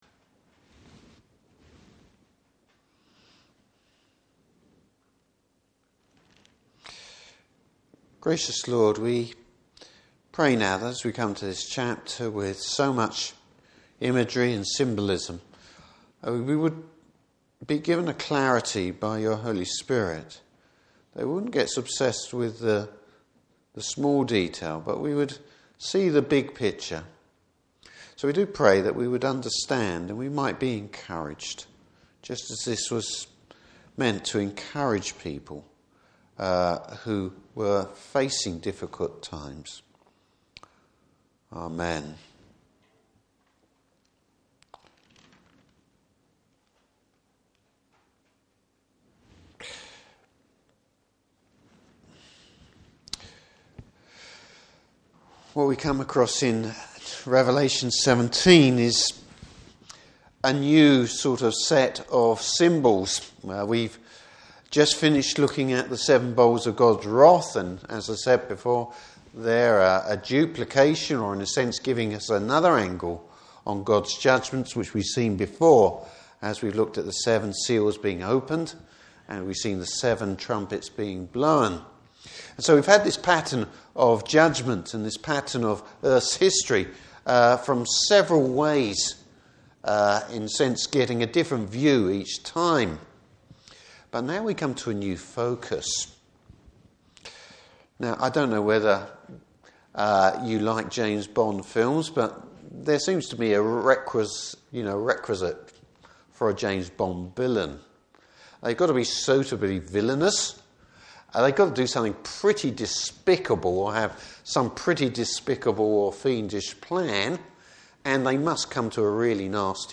Service Type: Evening Service Bible Text: Revelation 17.